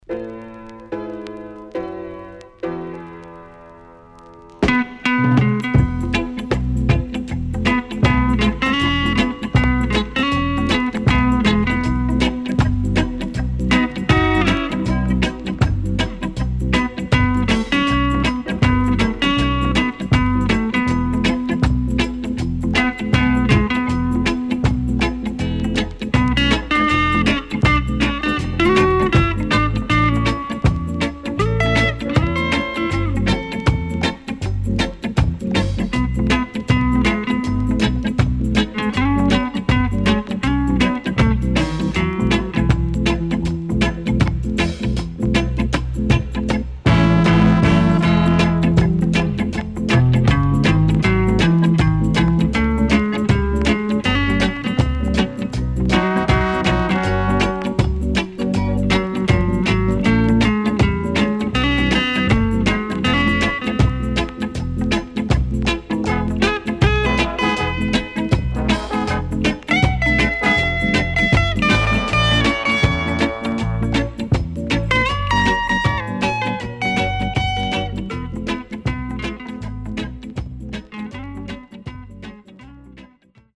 CONDITION SIDE A:VG+〜EX-
SIDE A:少しノイズ入りますが良好です。